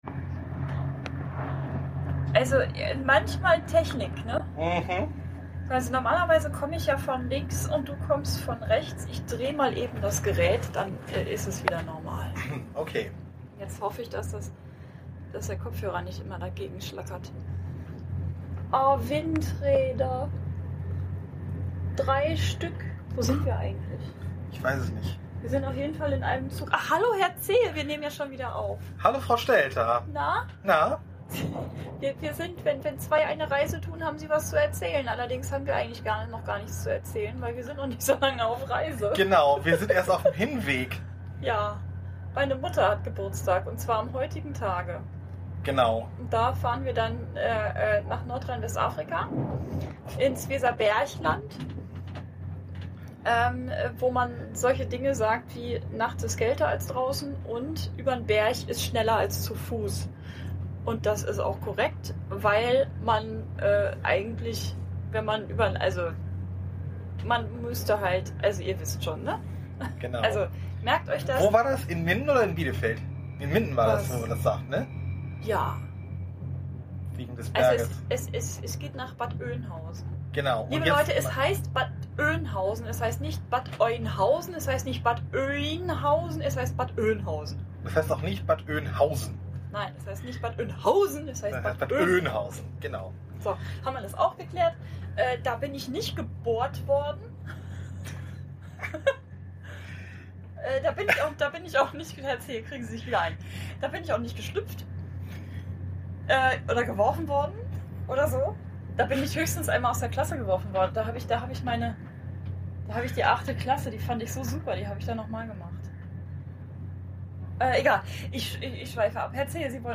Beschreibung vor 10 Jahren Wir waren auf Reisen und haben die Zugfahrt zwischen Hamburg und Hannover genutzt, um euch mal wieder mit ein paar Updates zu versorgen.